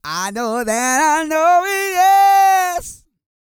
E-GOSPEL 233.wav